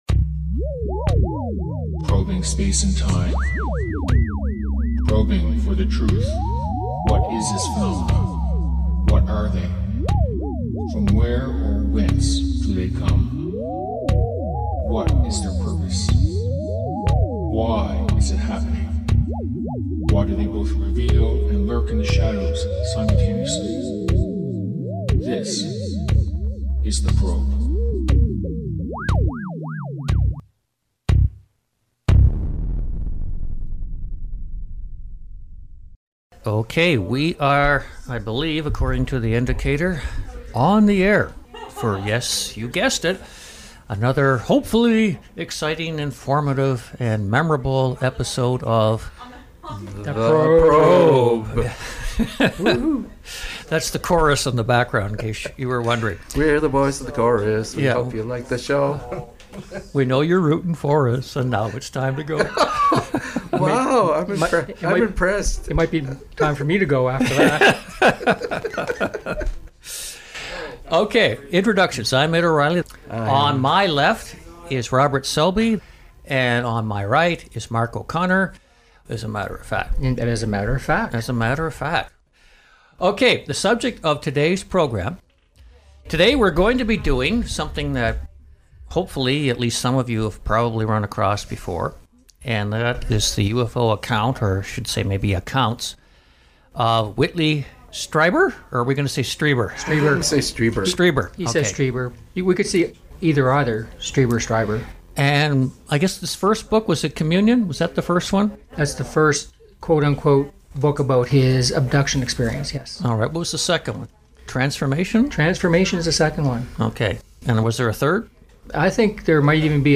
UFO Talk Show